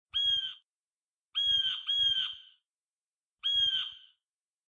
Bird 2.ogg